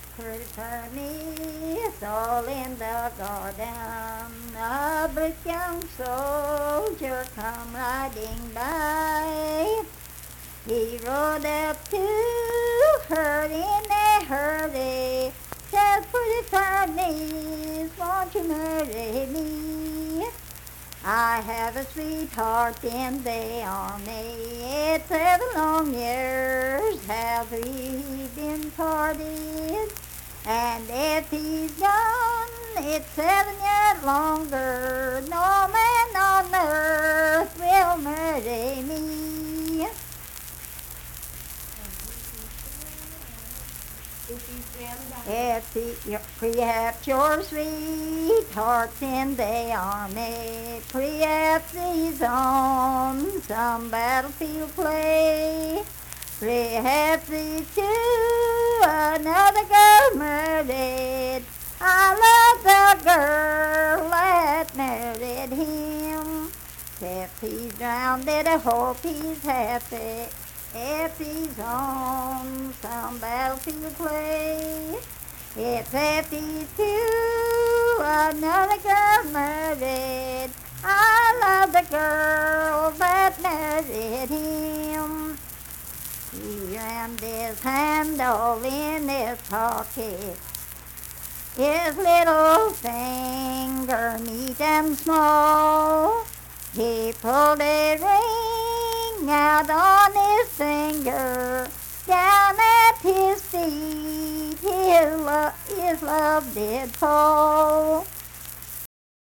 Pretty Fair Miss - West Virginia Folk Music | WVU Libraries
Unaccompanied vocal music performance
Verse-refrain 5(4).
Voice (sung)
Logan County (W. Va.)